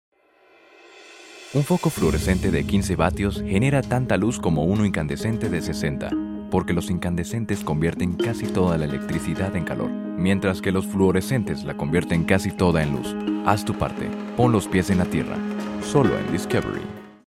Spanish - Latin American Neutral, Smooth and professional for presentations. Multifaceted for talents in commercials. Professional for announcer. Warm for Narrations. Multifaceted since young male till midle elegant male for voice over. a lot of voices, a lot of characters. Comic, fun. Versatil
Sprechprobe: Werbung (Muttersprache):